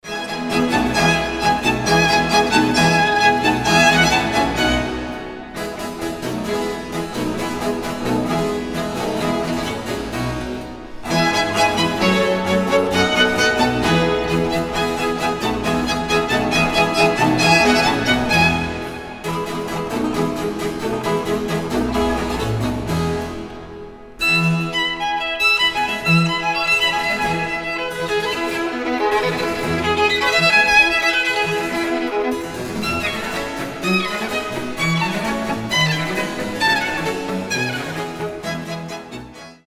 • Качество: 320, Stereo
без слов
скрипка
оркестр
воодушевляющие
Торжественная классическая скрипичная музыка